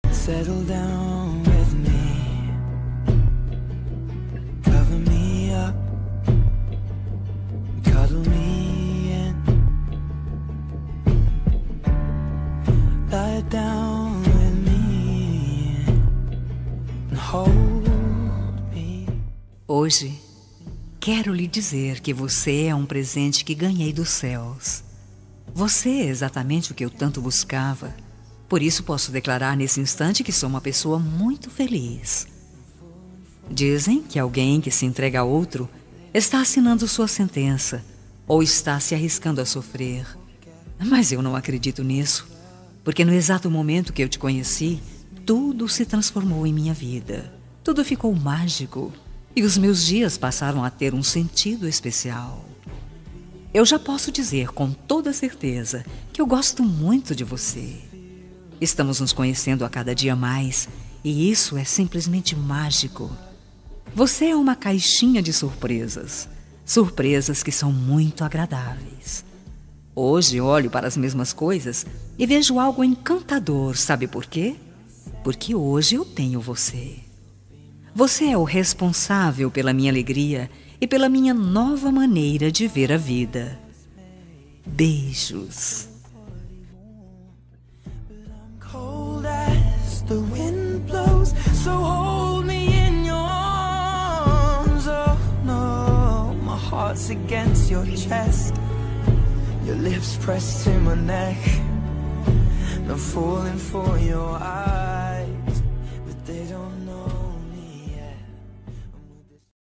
Telemensagem Para Ficante – Voz Feminina – Cód: 5429